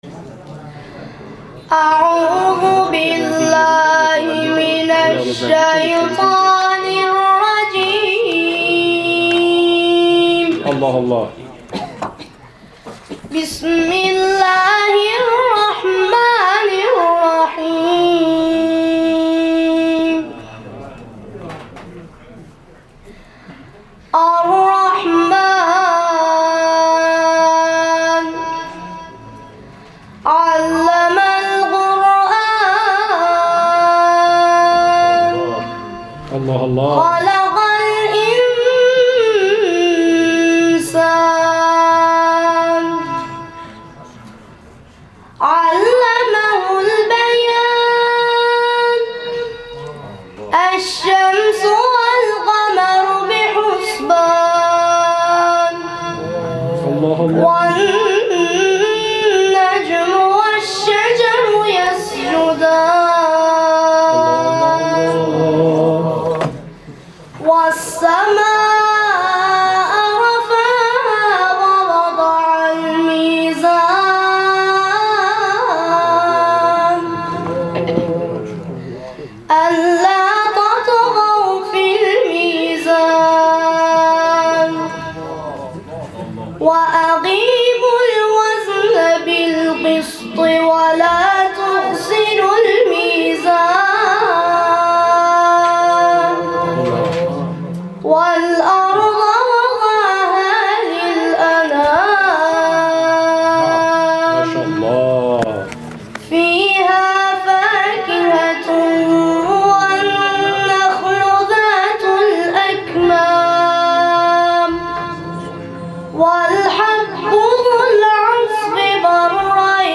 تلاوت‌های کوتاه